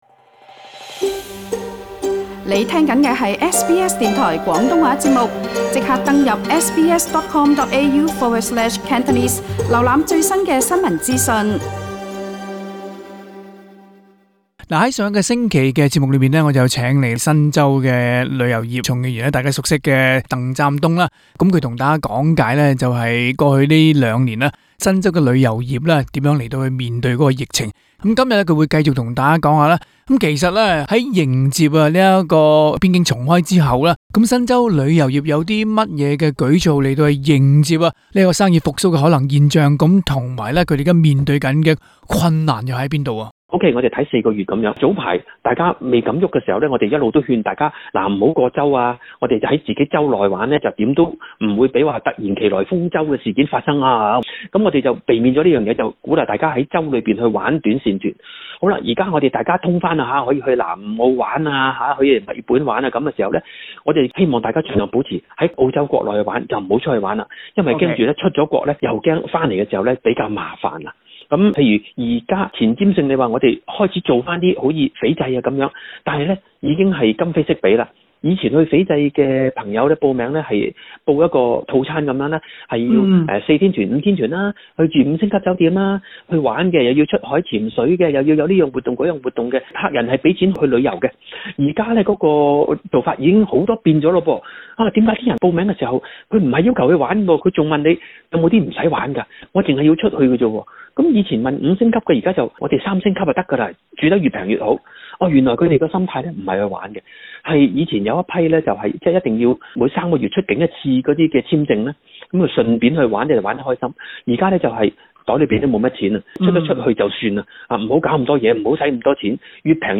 再上一次的訪談節目